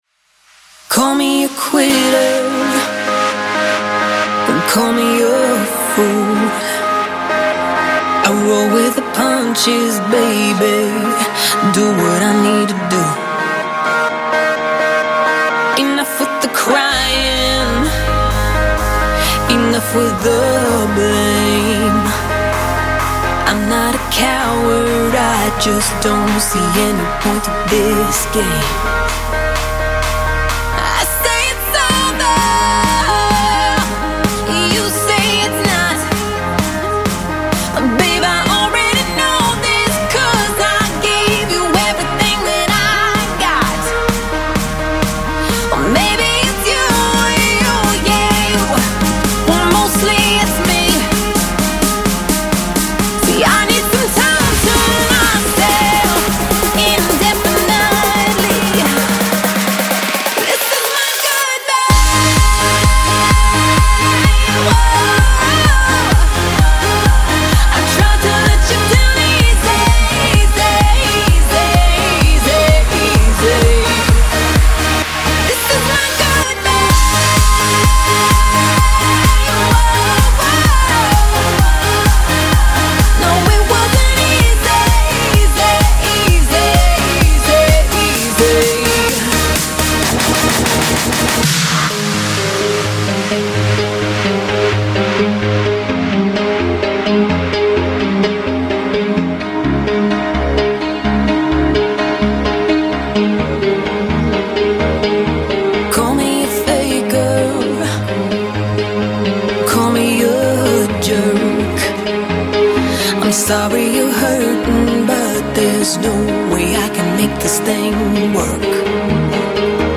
本期音乐为电子音乐专题，风格主要以House（浩室）为主。